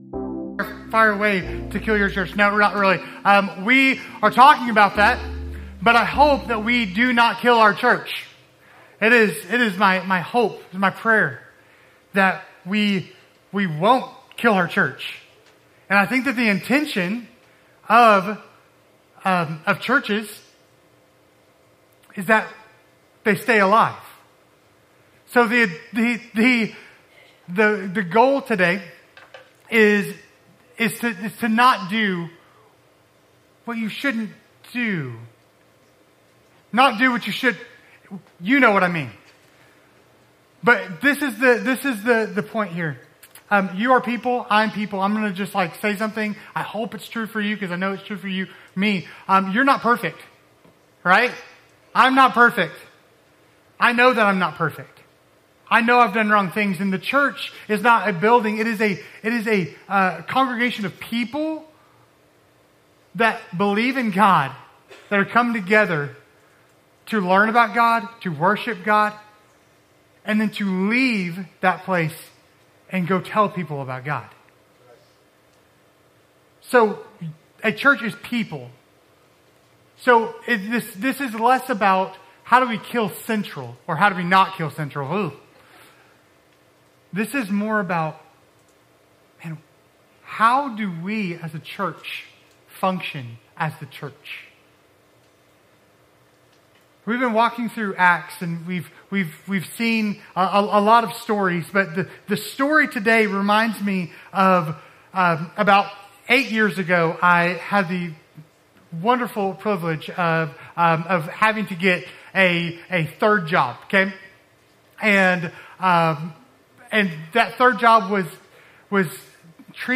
Sermons | Central Baptist Church Owasso
"So They May Hear," our summer sermon series deep diving into the book of Acts